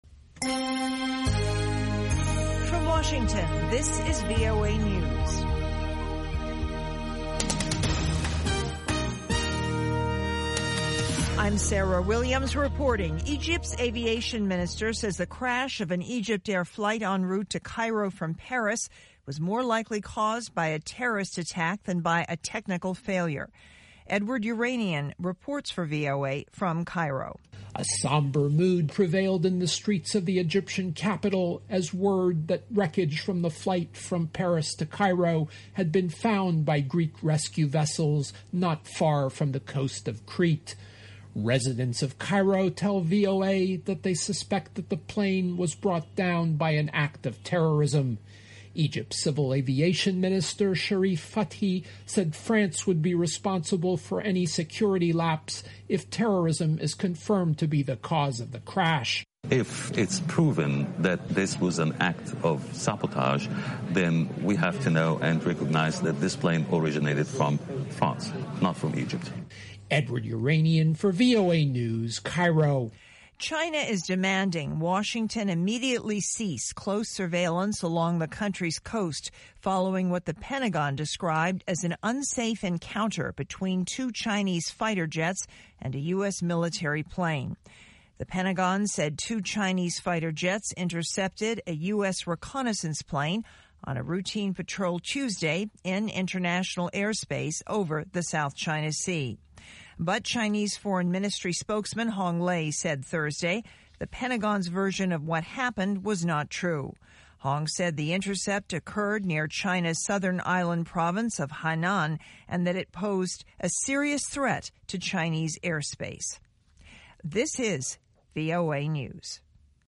1600 UTC Hourly Newscast in English